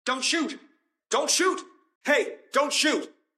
dont shut arc raiders Meme Sound Effect